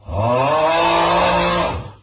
snore-2.ogg